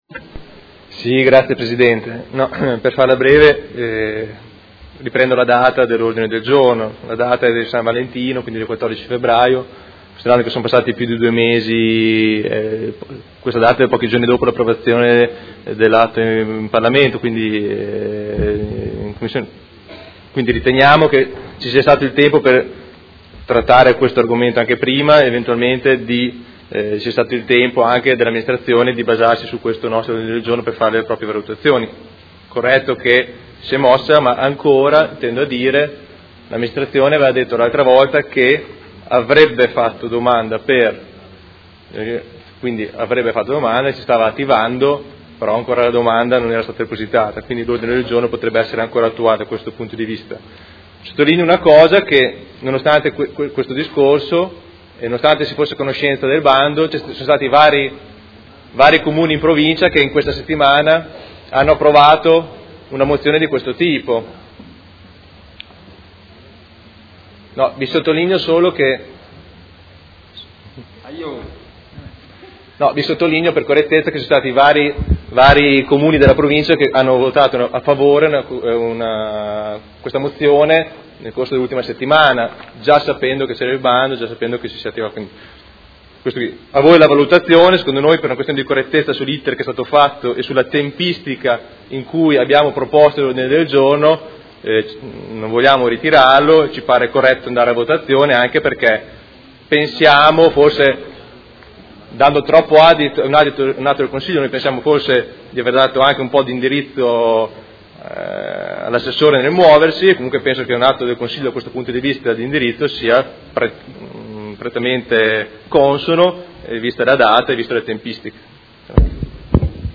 Seduta del 27/04/2017 Dibattito. Ordine del Giorno presentato dal Gruppo Movimento 5 Stelle avente per oggetto: Fondi per la sicurezza dei ciclisti